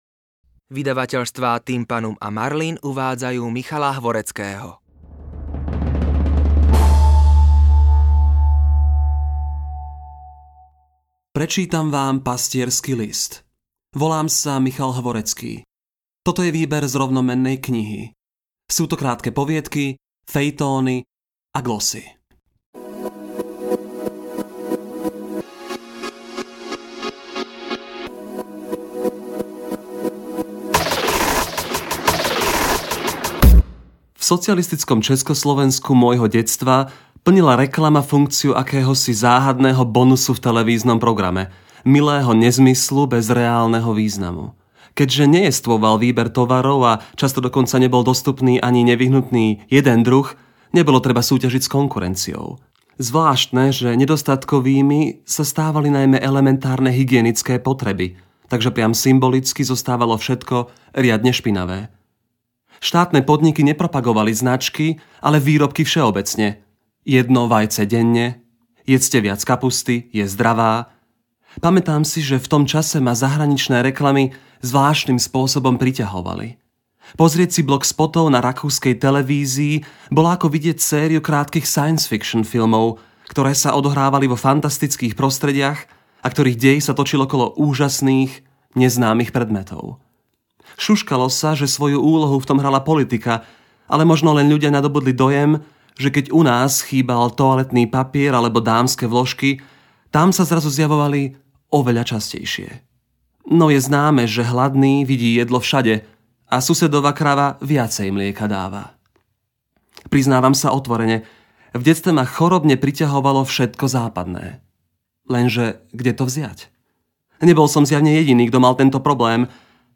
AudioKniha ke stažení, 16 x mp3, délka 56 min., velikost 63,9 MB, slovensky